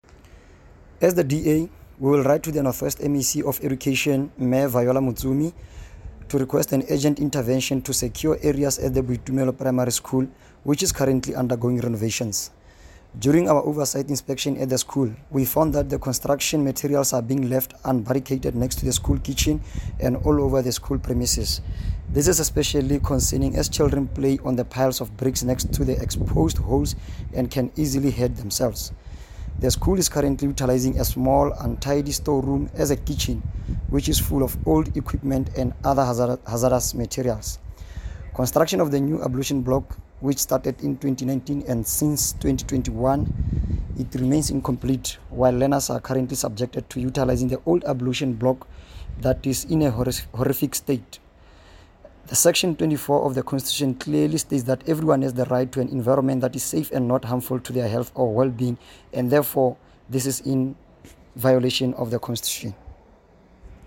Note to Broadcasters: Please find linked soundbites in
English and Setswana by Cllr Neo Mabote.